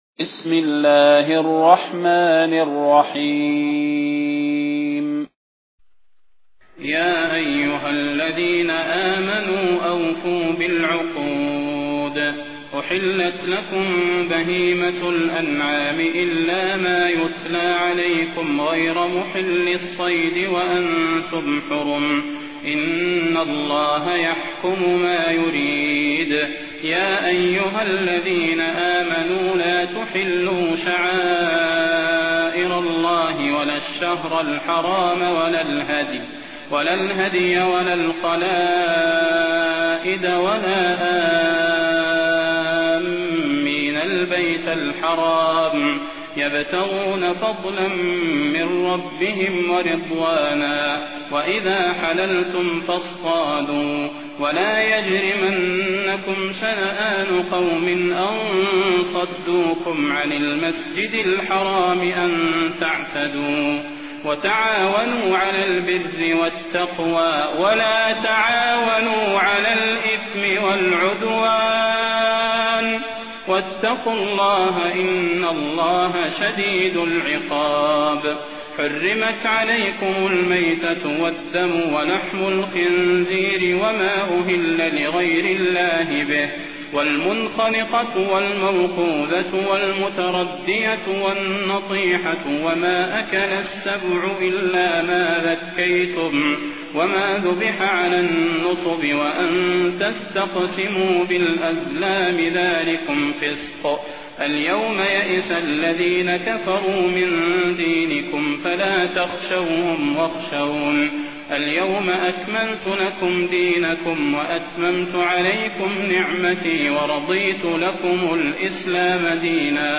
المكان: المسجد النبوي الشيخ: فضيلة الشيخ د. صلاح بن محمد البدير فضيلة الشيخ د. صلاح بن محمد البدير المائدة